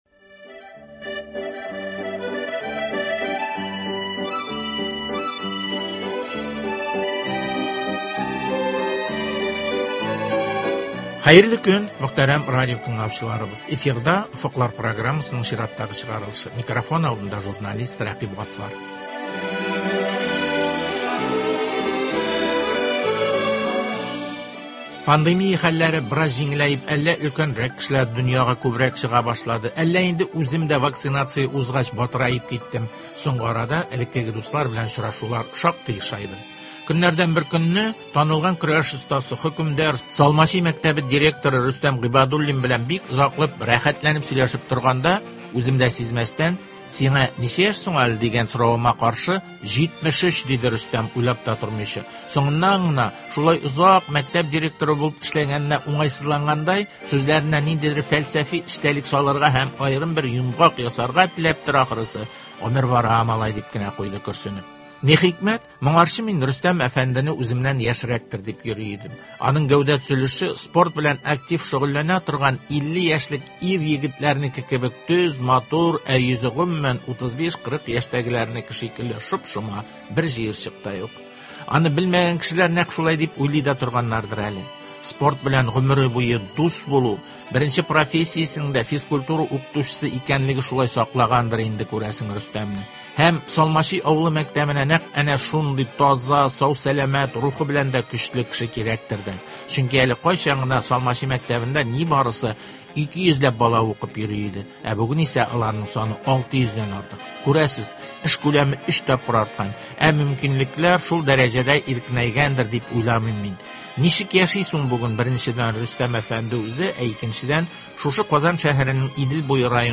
проблемалар хакында әңгәмә.